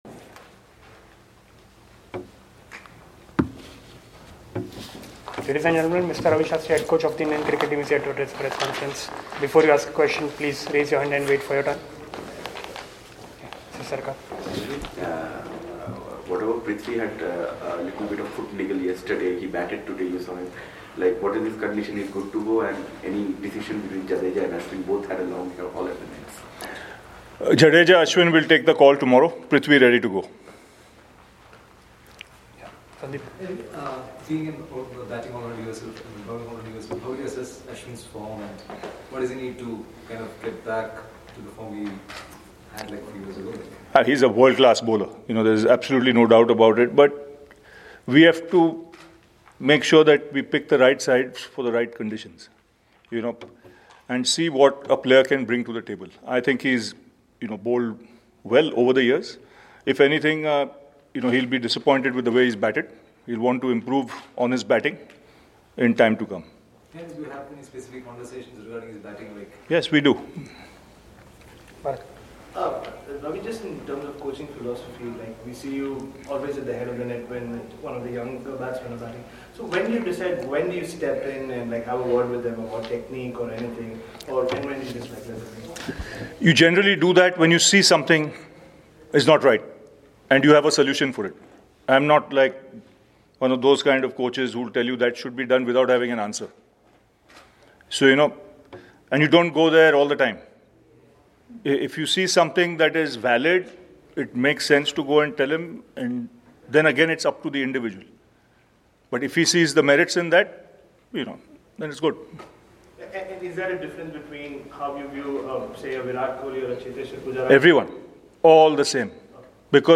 Ravi Shastri India’s Head Coach spoke to the media in Christchurch ahead of the 2nd Test against New Zealand.